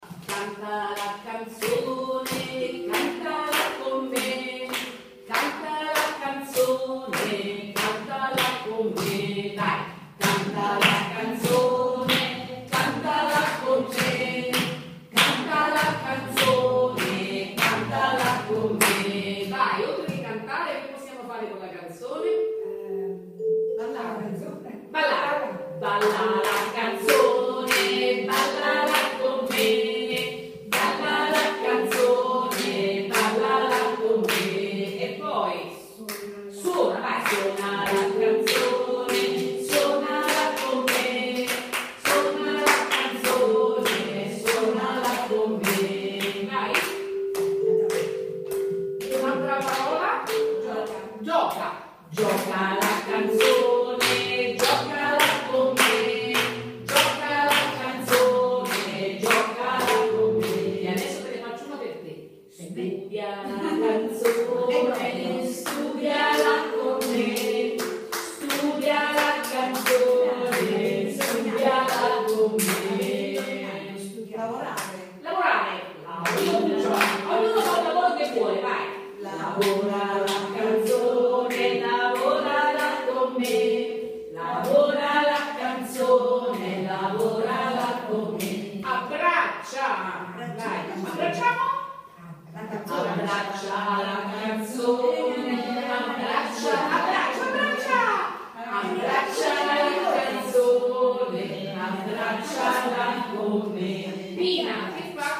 abbiamo lavorato sul mix canto-colore
cantalacanzone.mp3